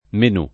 menu [fr. mënü^] s. m.; pl. (fr.) menus [id.] — italianizz. spesso (particolarm. nel parlato, e come term. d’informatica) in menù [